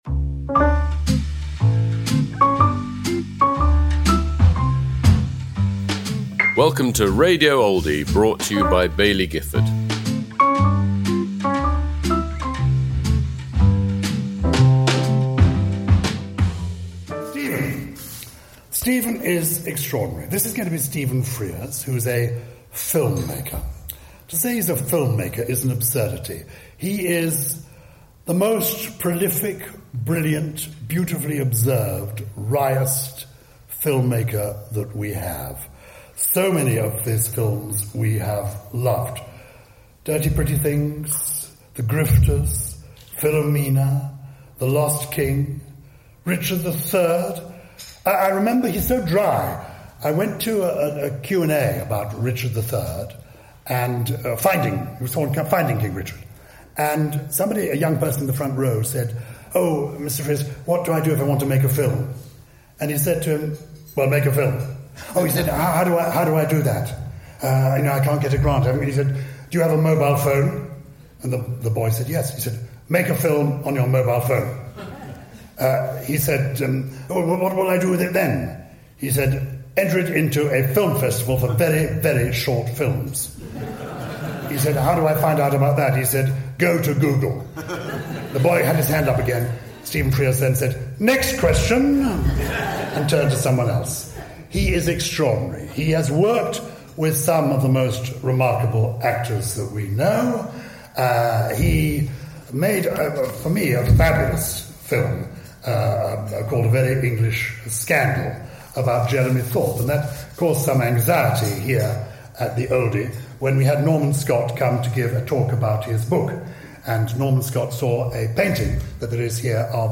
Stephen Frears at the 2025 Oldie of the Year Awards
Introduced by Gyles Brandreth.